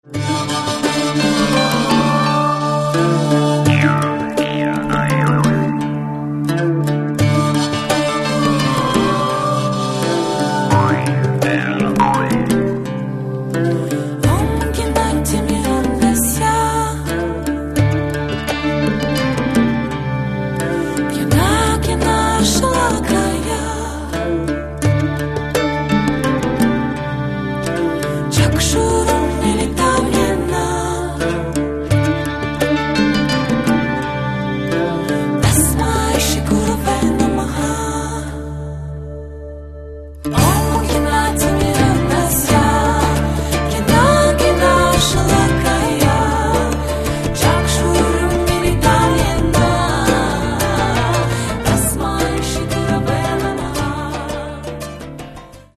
Каталог -> Рок и альтернатива -> Лирический андеграунд